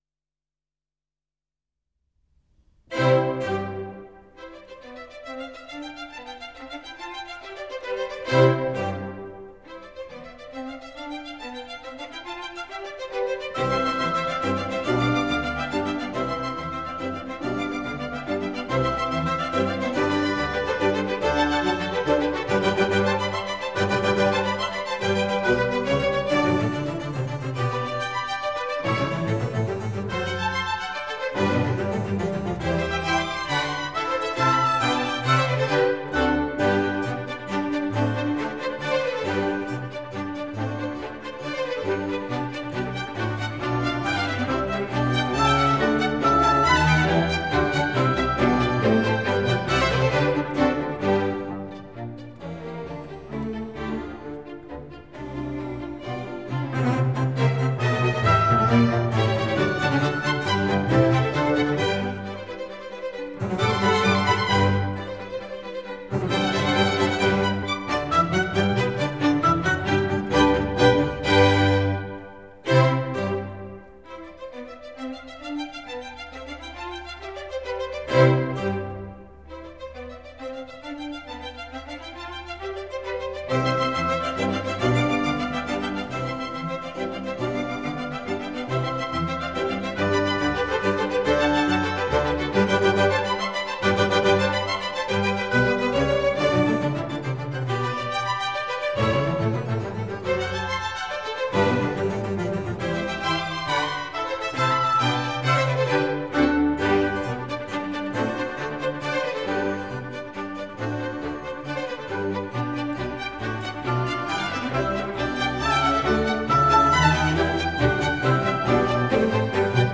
» 1 - Symphonies